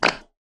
チューブから出す２